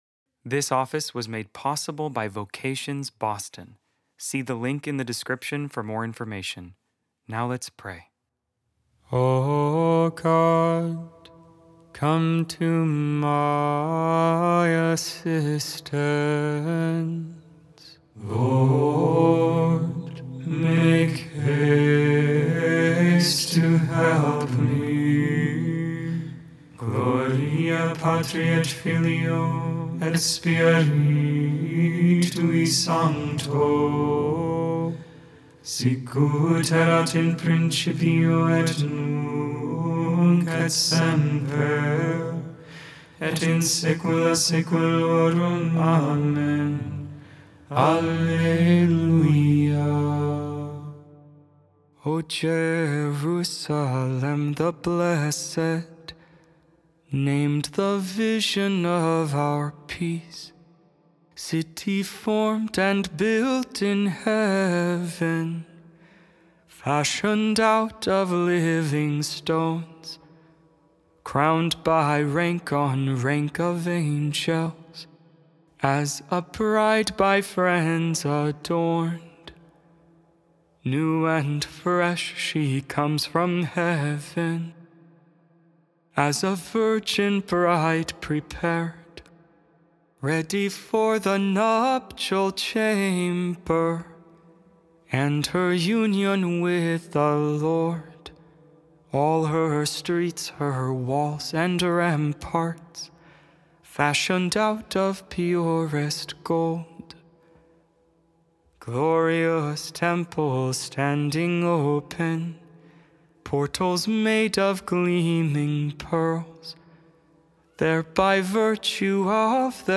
Vespers I, Evening Prayer for the 31st Saturday in Ordinary Time, November 8, 2025.Feast of the Dedication of the John Lateran Basilica Made without AI. 100% human vocals, 100% real prayer.